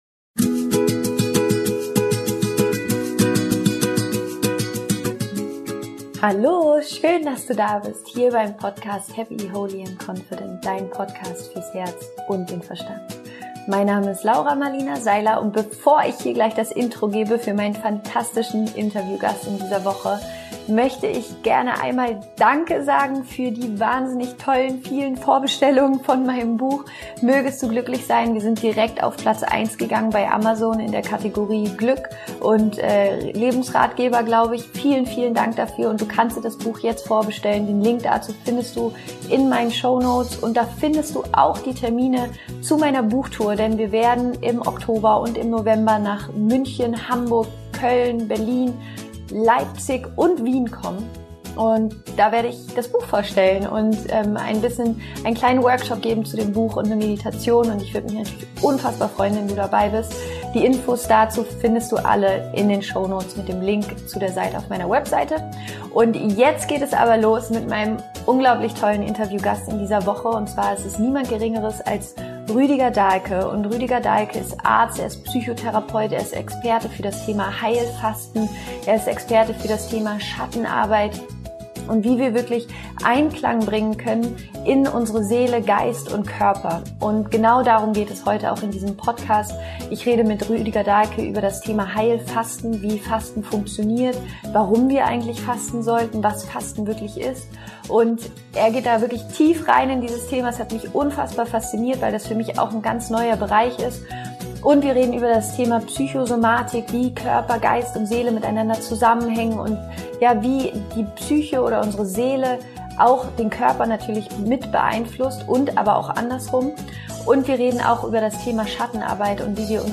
Wie du die Selbstheilungskräfte deines Körpers aktivierst - Interview mit Rüdiger Dahlke